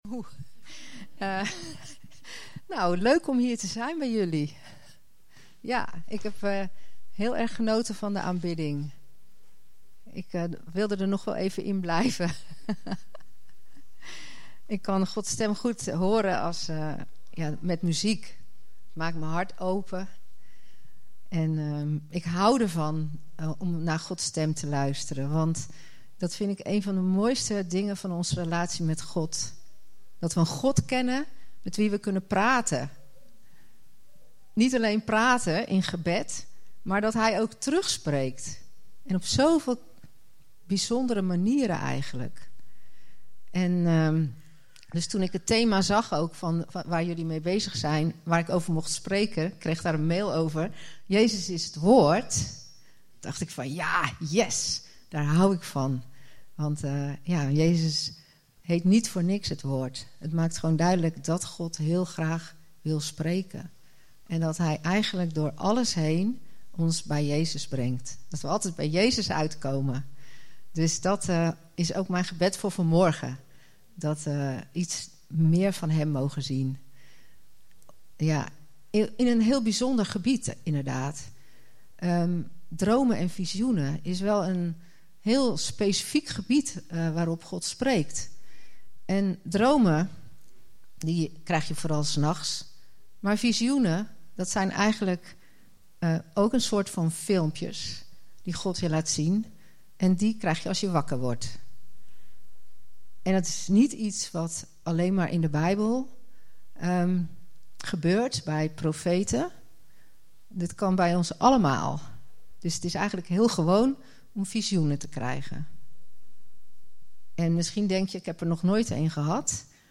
In deze podcast staan alle opgenomen toespraken van Leef! Zutphen vanaf 3 februari 2008 t/m nu.